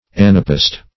anapaest - definition of anapaest - synonyms, pronunciation, spelling from Free Dictionary
\An`a*p[ae]st\